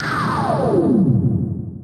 snd_fall_cool_deep.wav